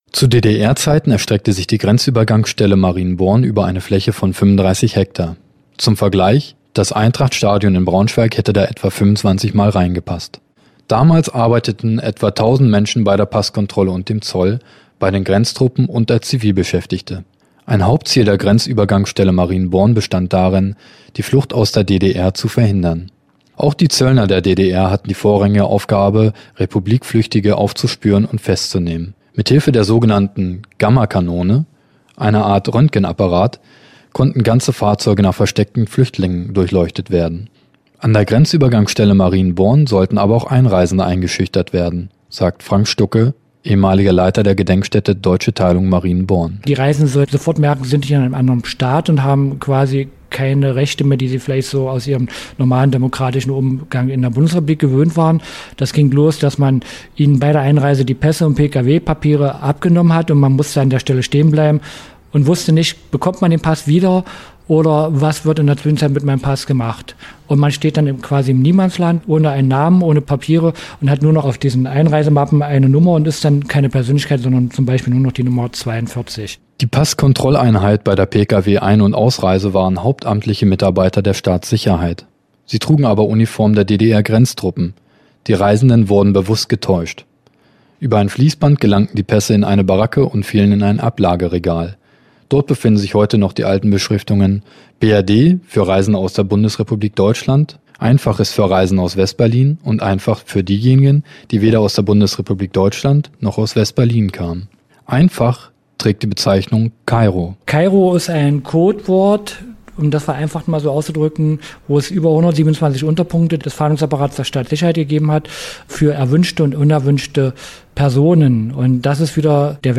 Die Gedenkstätte deutsche Teilung in Marienborn bei Helmstedt ist einer der wichtigsten Erinnerungsorte in unser Region. Neben Ausstellungen über die Geschichte der innerdeutschen Grenze bietet sie eine Fülle von Informationen über den Ost-West-Konflikt und die Zeit vor und nach der Wende. Ein Hausbesuch